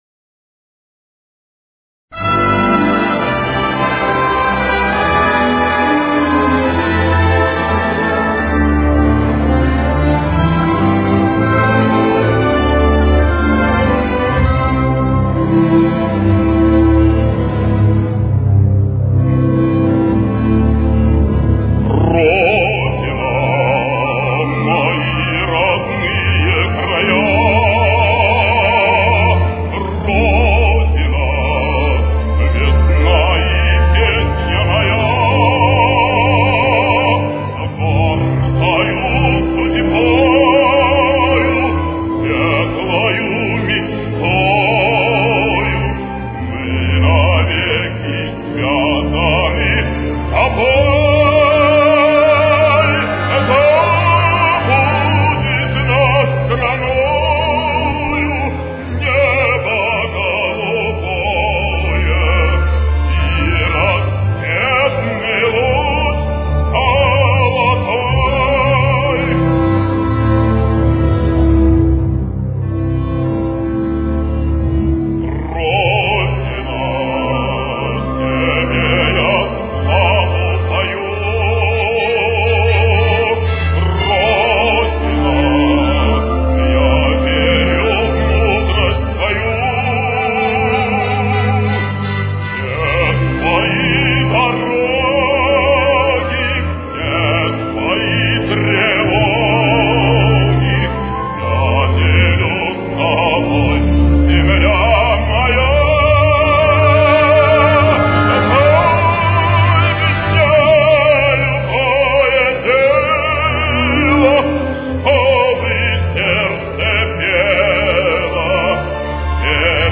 советский и российский оперный певец (баритональный бас).